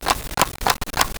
Clock Ticking 3
clock-ticking-3.wav